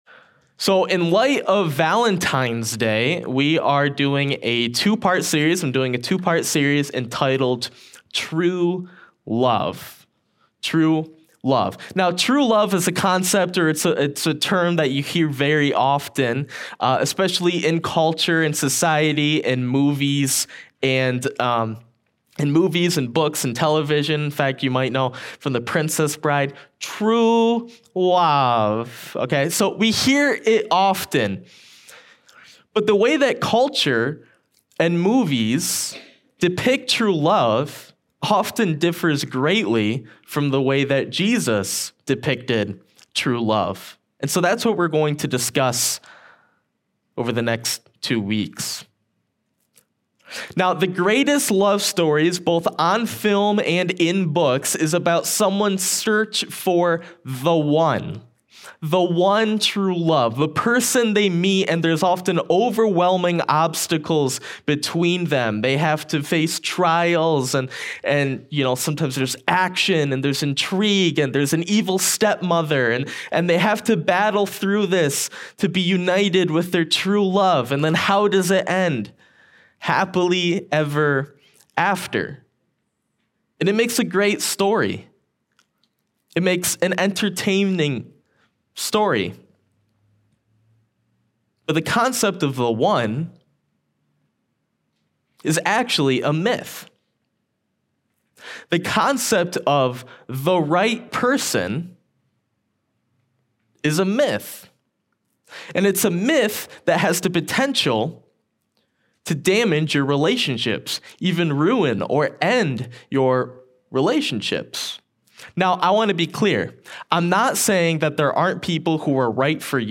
2024 Current Message True Love